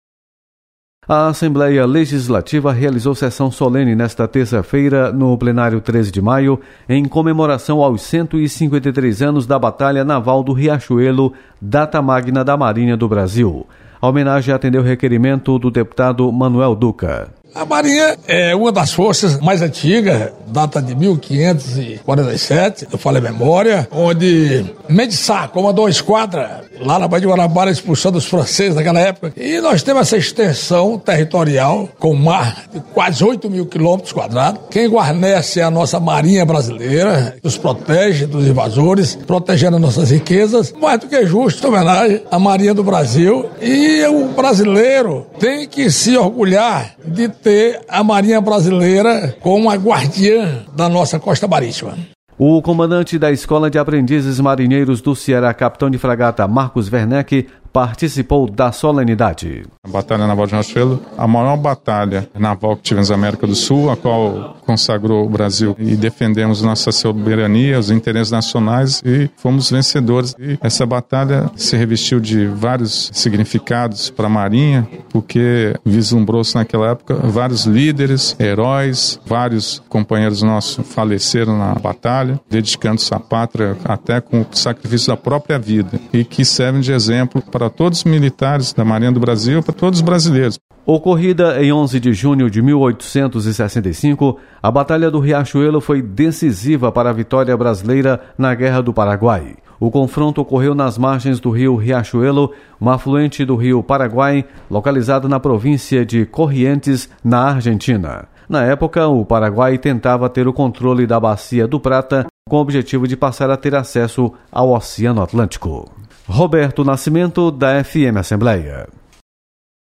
Sessão solene comemora os 153 anos da Batalha Naval do Riachuelo nesta terça-feira. Repórter